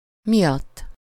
Ääntäminen
Ääntäminen France: IPA: [puʁ] Haettu sana löytyi näillä lähdekielillä: ranska Käännös Ääninäyte 1. miatt 2.